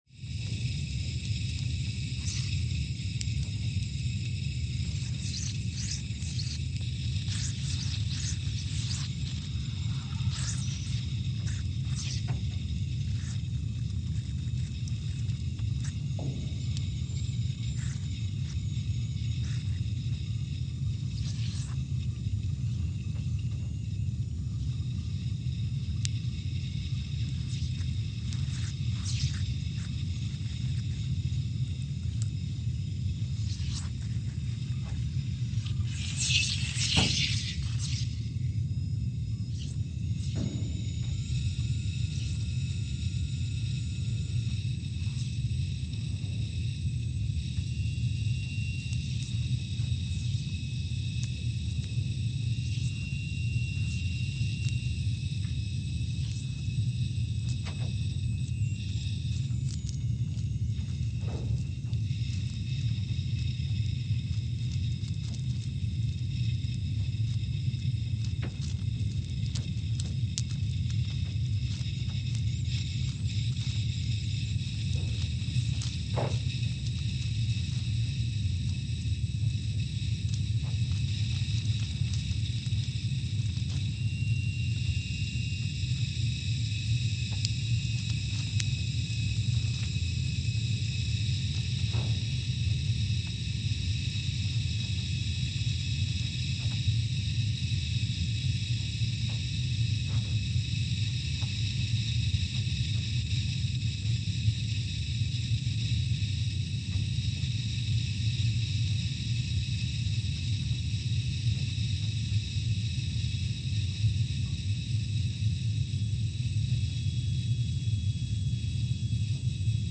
Scott Base, Antarctica (seismic) archived on July 15, 2021
No events.
Station : SBA (network: IRIS/USGS) at Scott Base, Antarctica
Speedup : ×500 (transposed up about 9 octaves)
Loop duration (audio) : 05:45 (stereo)